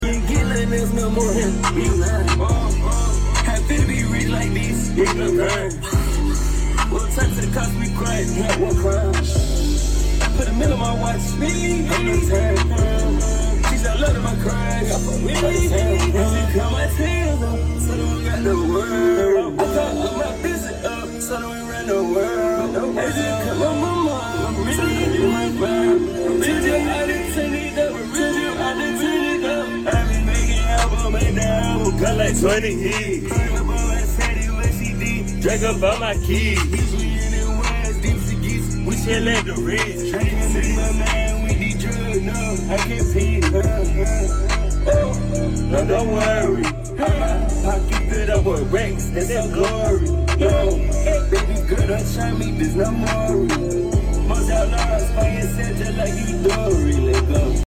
in an Instagram Live